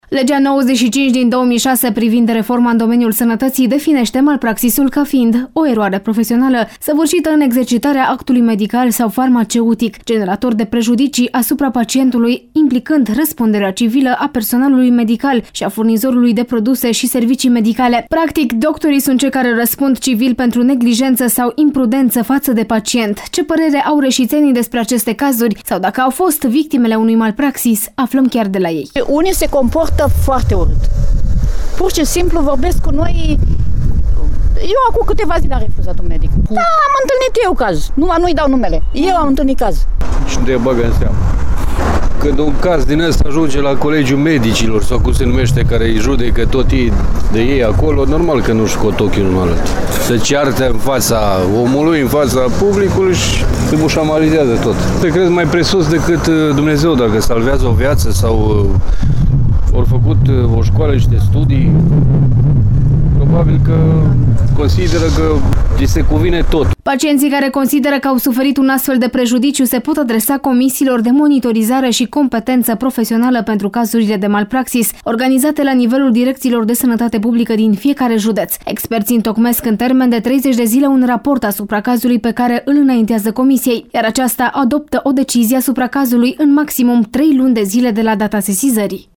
Nu există judeţ în România în care să nu fi fost sesizat măcar un caz de malpraxis astfel că judeţul Caraş-Severin nu face excepţie. Ascultaţi un reportaj